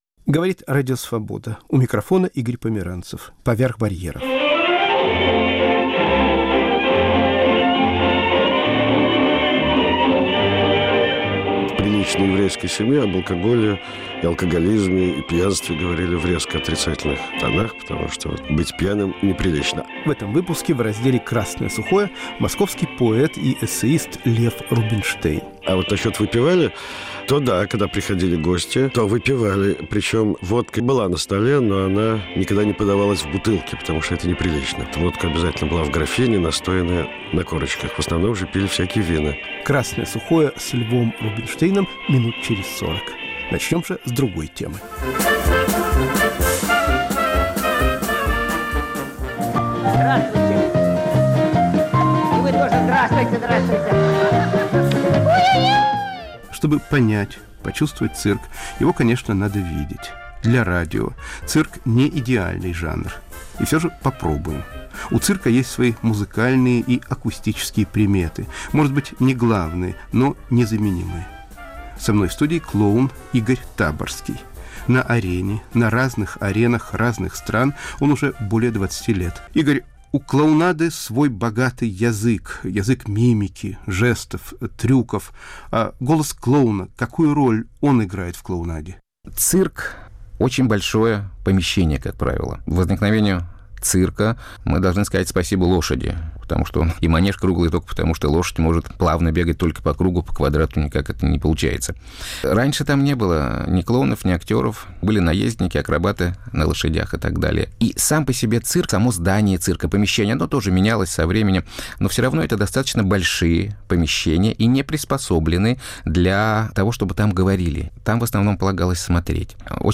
Голоса клоунов. Передача об акустических свойствах циркового искусства.